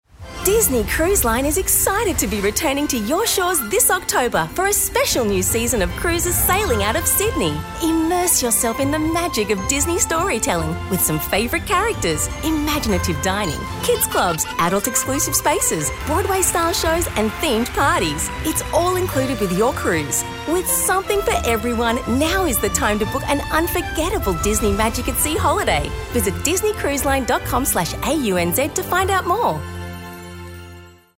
Home / Work / Voiceover / Disney Cruises